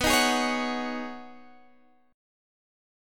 B6add9 Chord (page 2)
Listen to B6add9 strummed